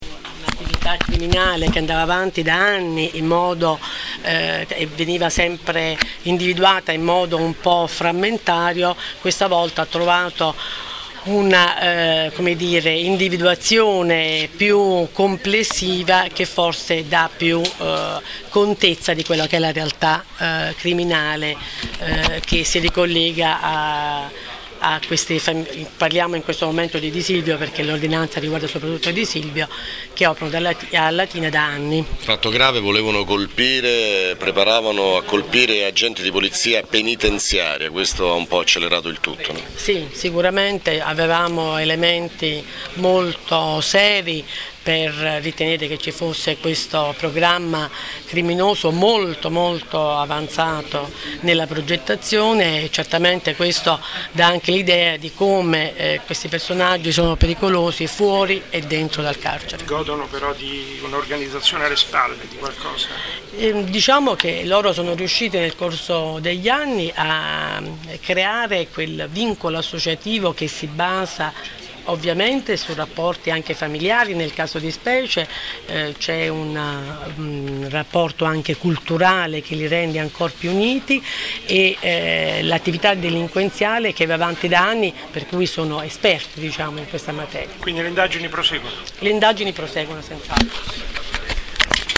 Intervista procuratore aggiunto Nunzia D’Elia